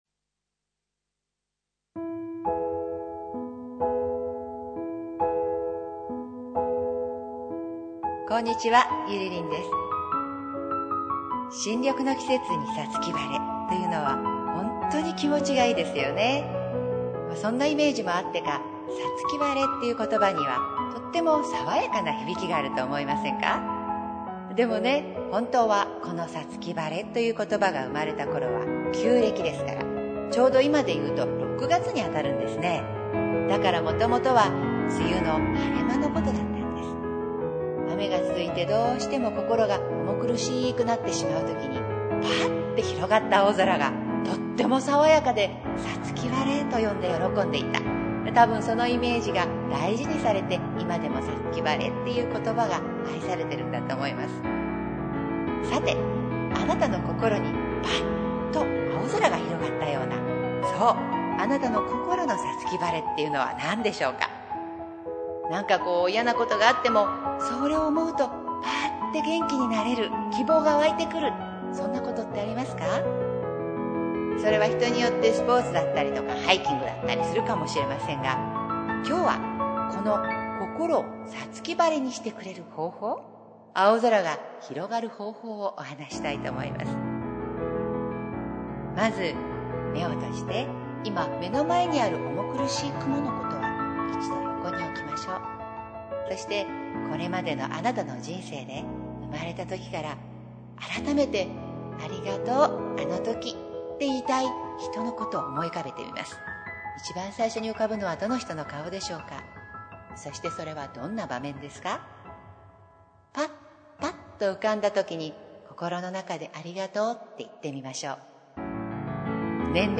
３分間フリートークです。